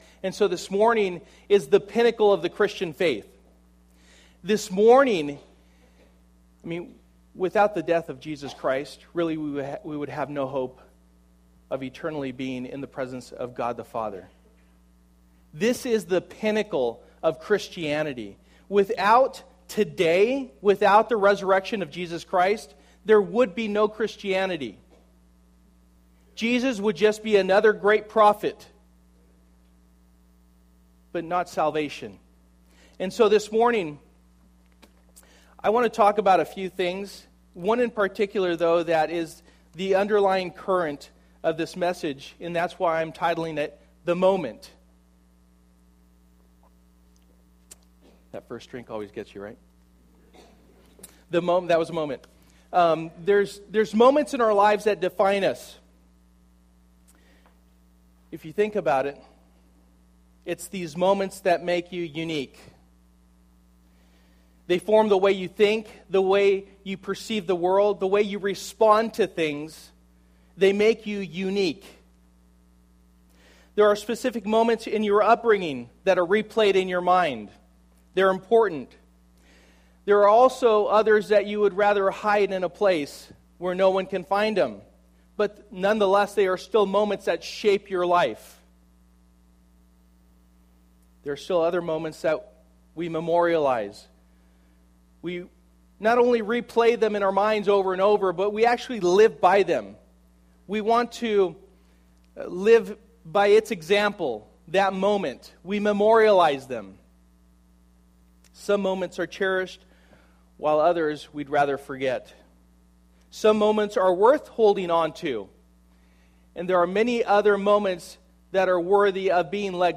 Luke 7:36 Service: Sunday Morning Luke 7:36 « After God’s Heart